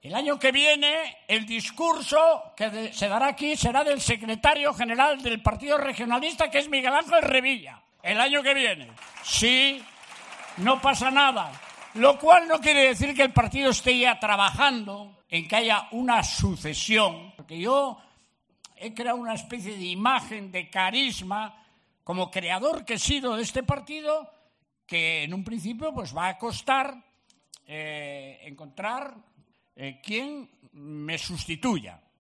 Durante la comida de Navidad, el secretario general de los regionalistas asegura que nadie la va a poner una pistola para designar a su sustituto